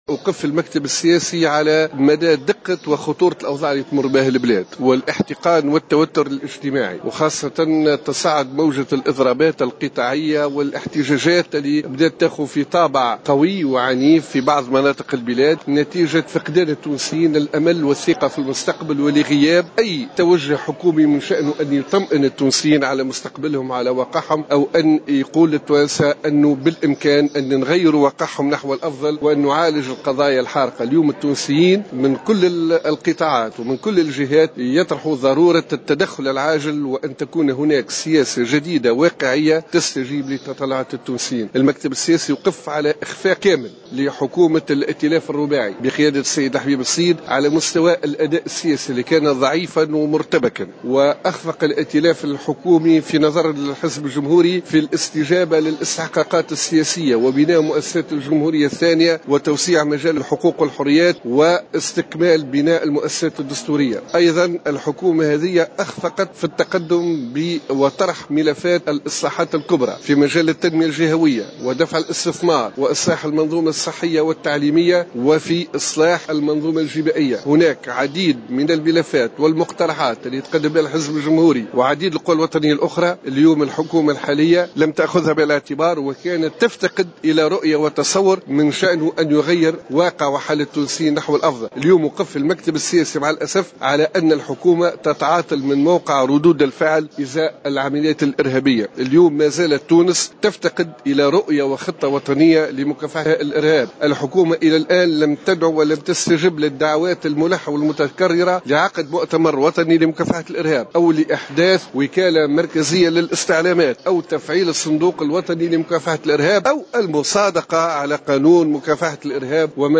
انعقد اليوم الأحد المكتب السياسي للحزب الجمهوري بالحمامات، وتوقف الحزب على ما وصفه بالاخفاق الكامل لحكومة الرباعي الحاكم وفق ما صرح به للجوهرة أف أم الناطق باسم الحزب عصام الشابي.